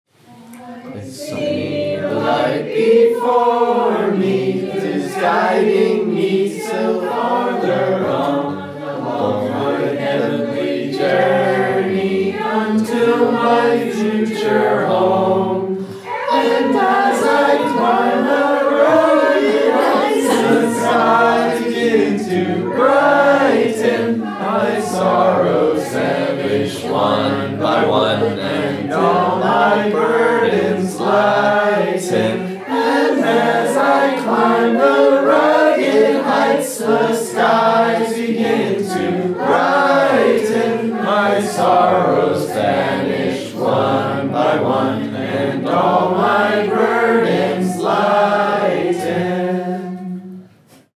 Each week, members of the choral study group assemble to learn a thematically related archival piece of vocal music by ear.
Week 1 Rehearsal